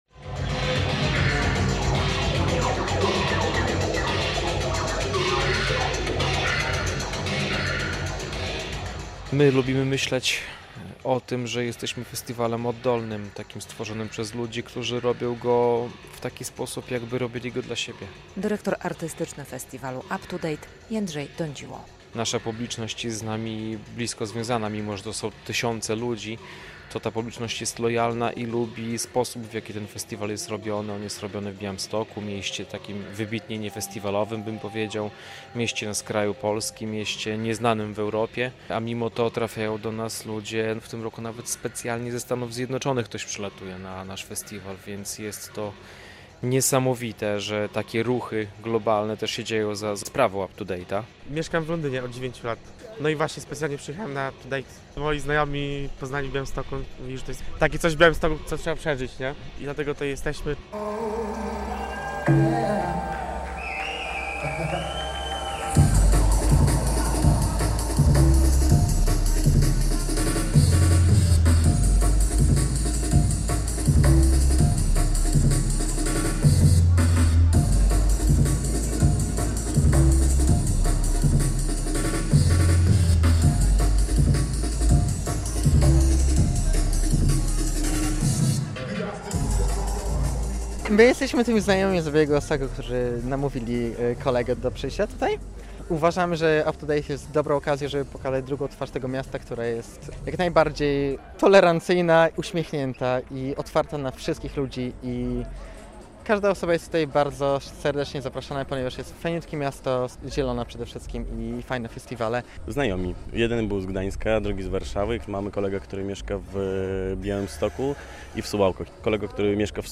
Jedna z najważniejszych imprez muzycznych w Polsce - Up To Date Festival - rozpoczęła się w piątek (3.09) wieczorem. Usłyszymy różne gatunki muzyki elektronicznej.